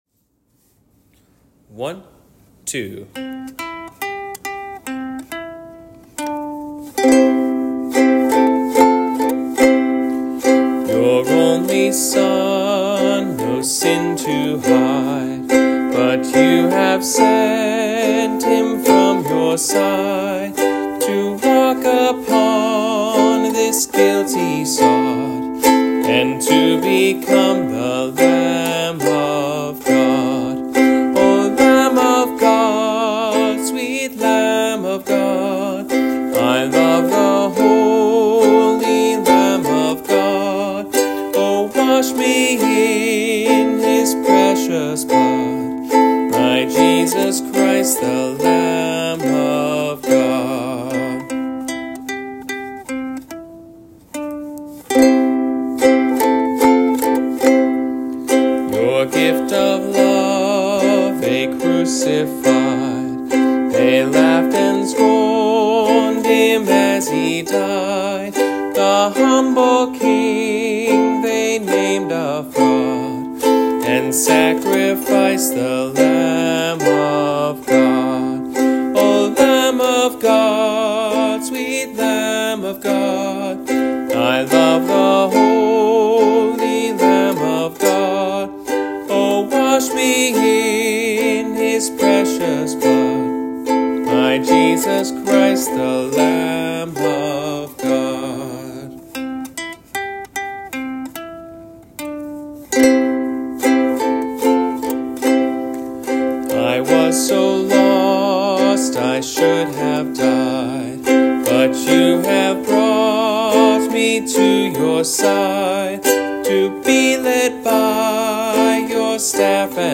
Ukulele Rehearsal Tracks — Dutilh Church
Ukulele
O+Lamb+of+God+-+Ukulele.m4a